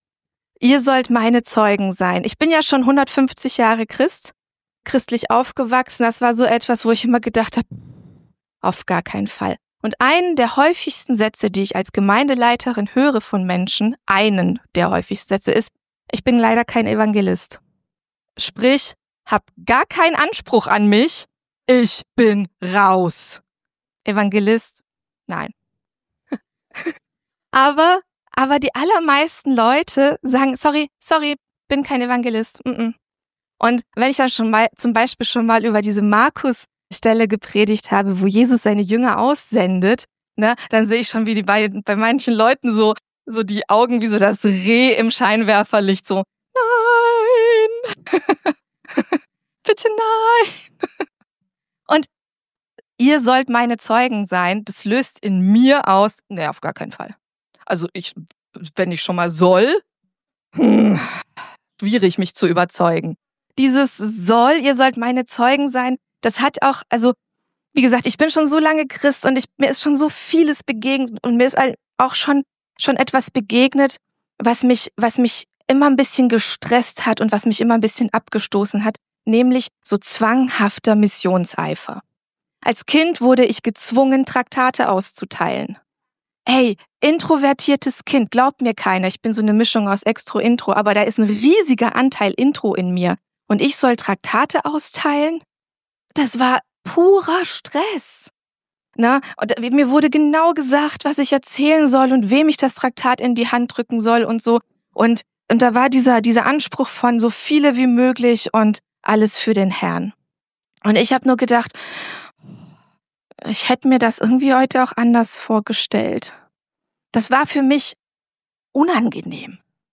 Erst dieses Video schauen, dann die Predigt hören: Rentiere in Hammerfest 😉